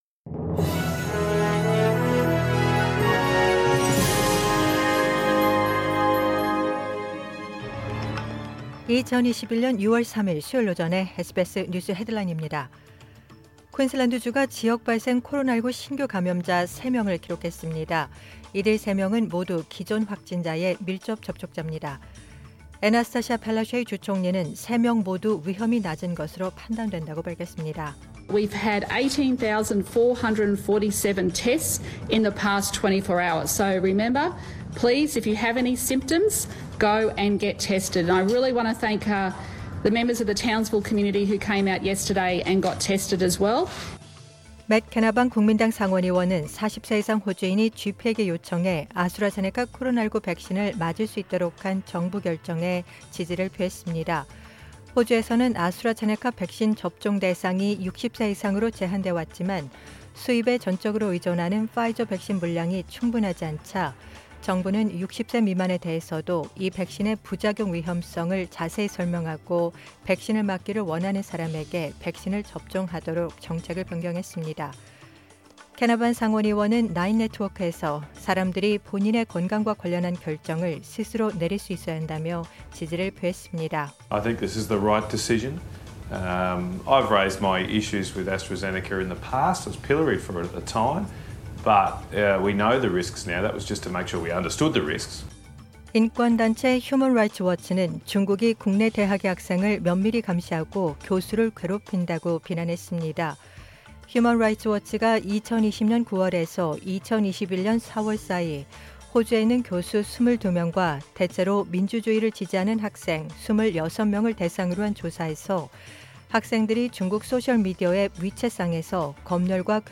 2021년 6월 30일 수요일 오전의 SBS 뉴스 헤드라인입니다.